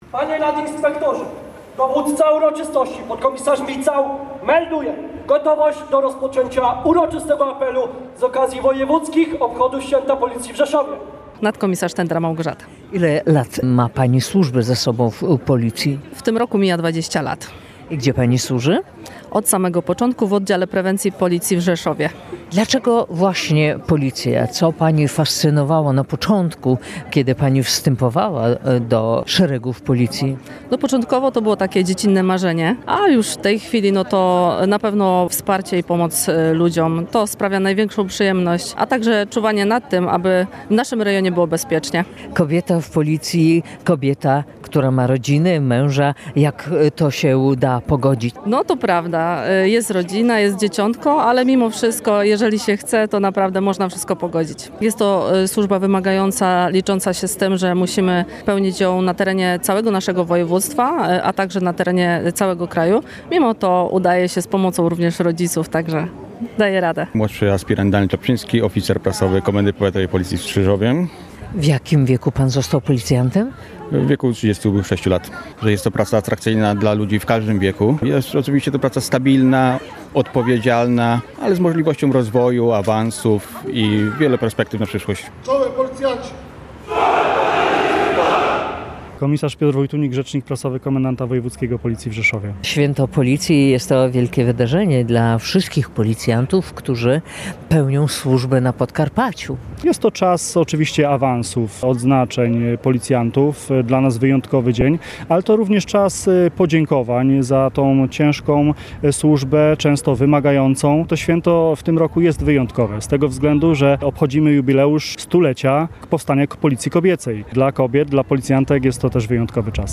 W Rzeszowie odbyły się uroczystości wojewódzkie.